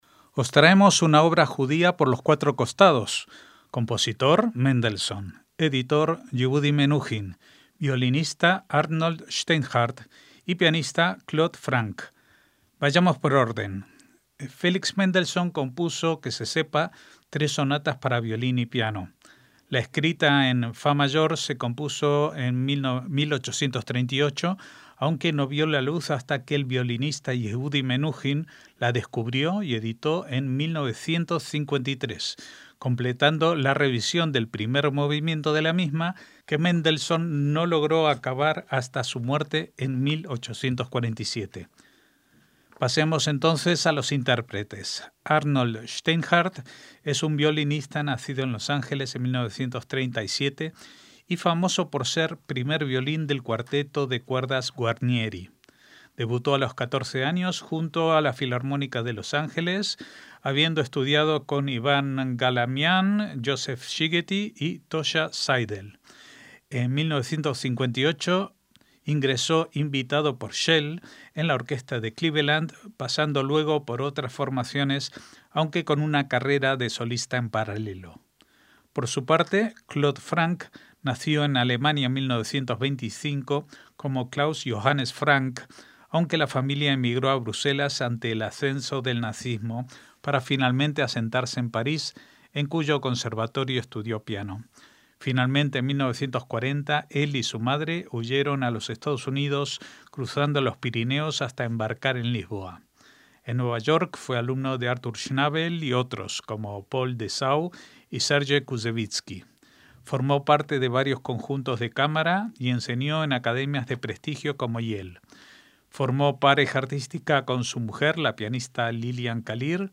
MÚSICA CLÁSICA - Os traemos una obra judía por los cuatro costados: compositor (Mendelssohn), editor (Yehudi Menuhin), violinista (Arnold Steinhardt) y pianista (Claude Frank).
Felix Mendelssohn compuso, que se sepa, tres sonatas para violín y piano.